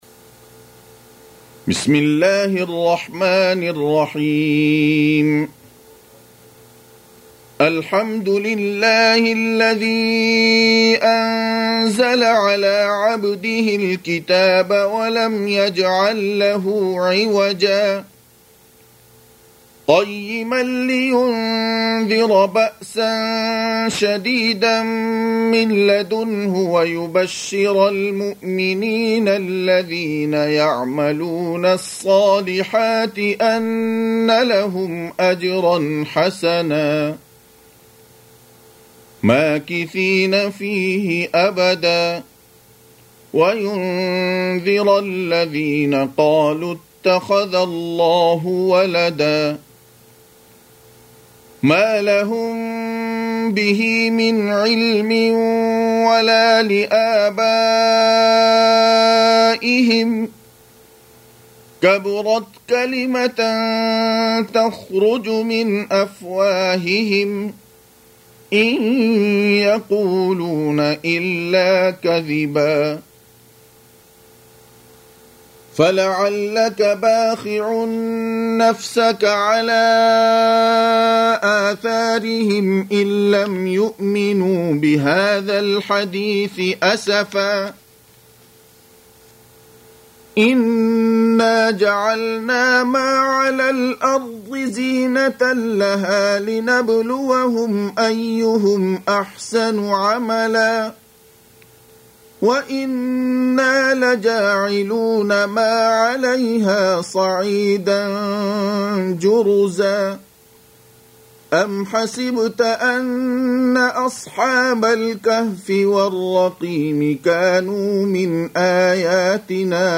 18. Surah Al-Kahf سورة الكهف Audio Quran Tarteel Recitation
Surah Repeating تكرار السورة Download Surah حمّل السورة Reciting Murattalah Audio for 18.